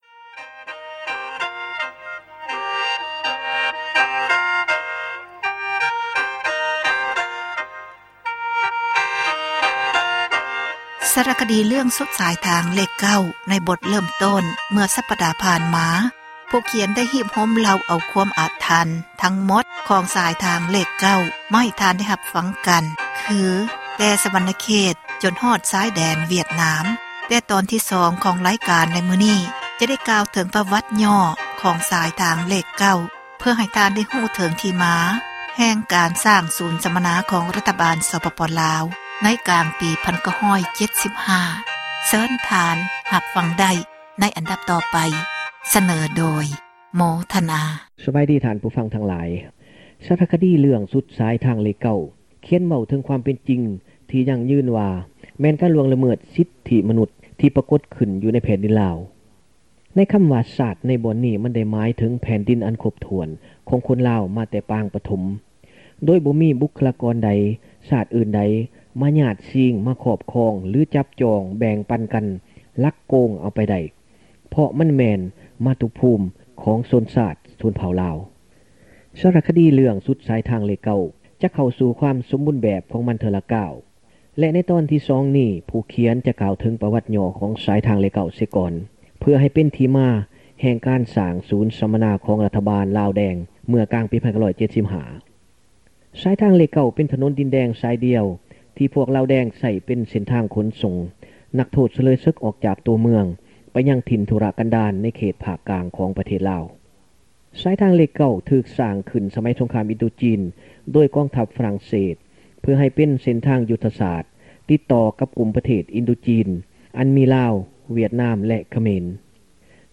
ສາຣະຄະດີ ເຣື້ອງ “ສຸດສາຍທາງເລຂ 9” ເອເຊັຽເສຣີ ຈະນໍາມາ ອອກອາກາດ ອີກເພື່ອ ຕອບສນອງ ຄວາມຮຽກຮ້ອງ ຂອງ ທ່ານຜູ້ຟັງ.